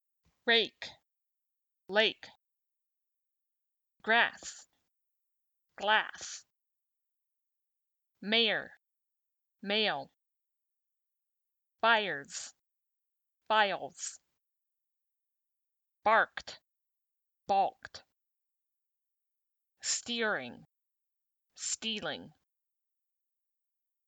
You can hear the difference between /r/ and /l/ in these words.
compare_r-l_words.mp3